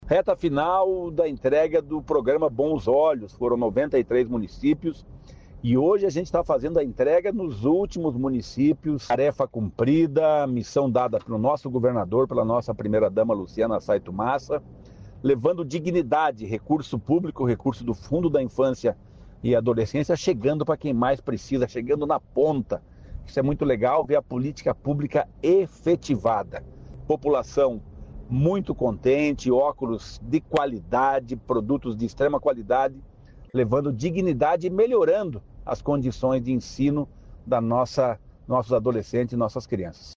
Sonora do secretário estadual do Desenvolvimento Social e Família, Rogério Carboni, sobre o programa Bons Olhos